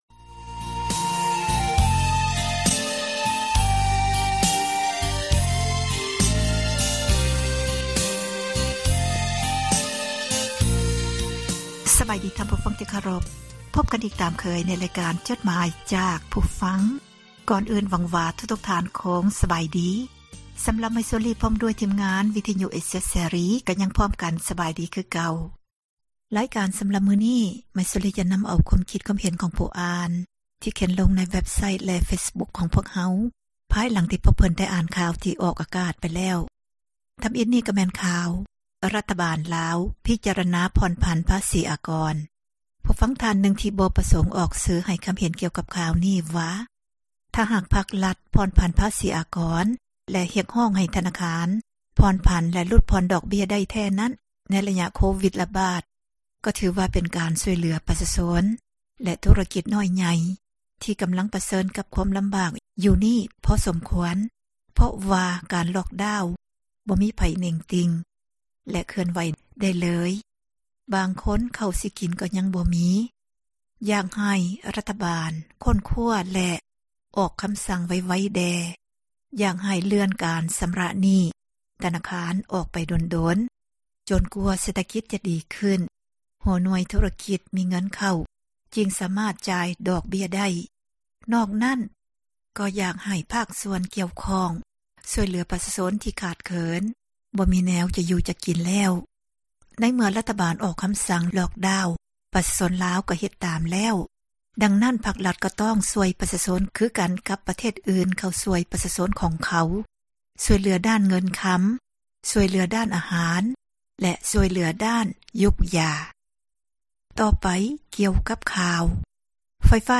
ອ່ານຈົດໝາຍ, ຄວາມຄຶດຄວາມເຫັນ ຂອງທ່ານ ສູ່ກັນຟັງ